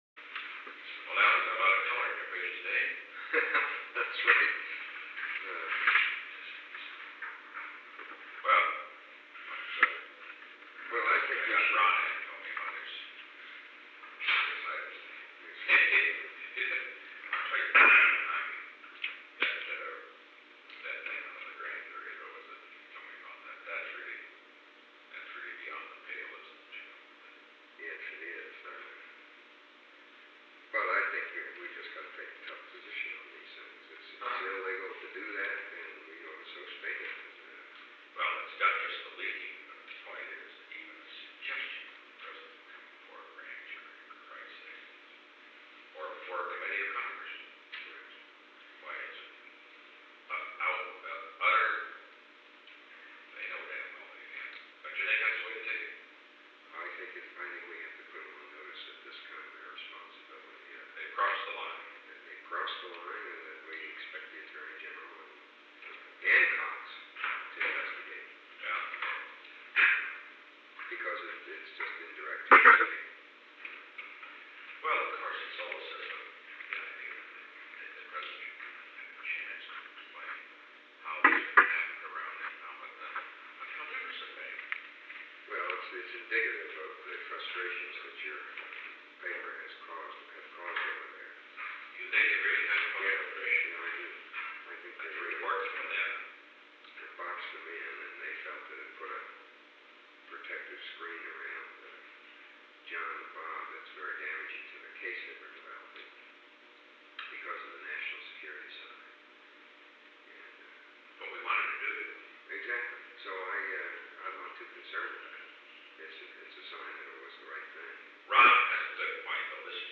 Secret White House Tapes | Richard M. Nixon Presidency